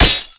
rifle_hit_glass1.wav